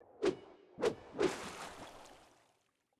sword 3.wav